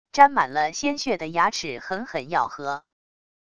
沾满了鲜血的牙齿狠狠咬合wav音频